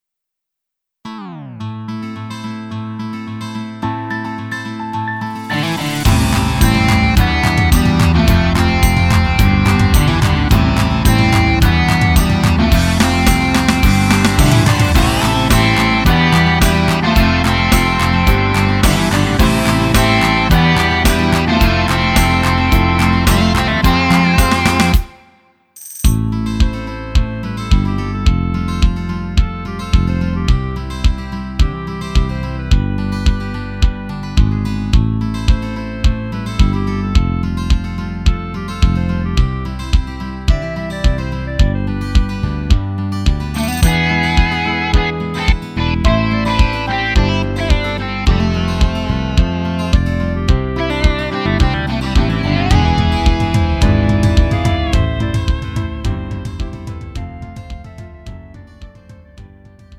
음정 원키 3:00
장르 가요 구분